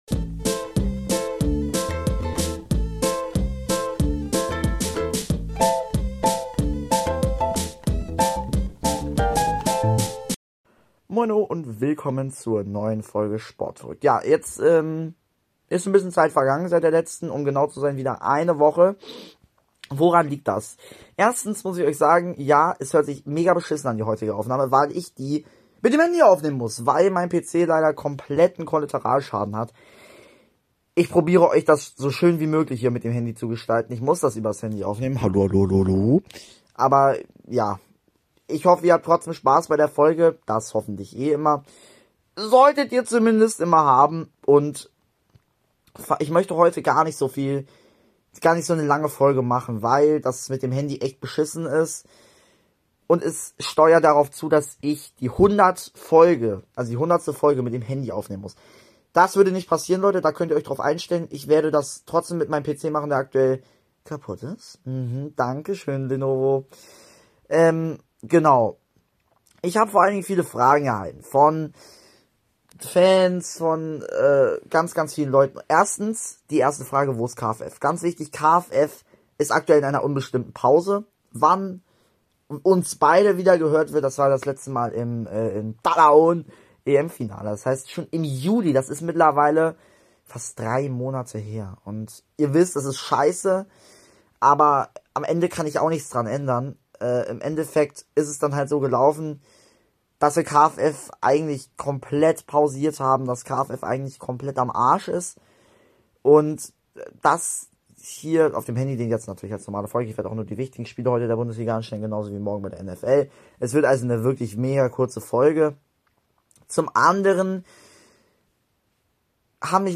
Beschreibung vor 1 Jahr Hallo meine Lieben, erstmal sorry für die technische Tonqualität. Heute müssen wir üner Bayer Leverkusen reden.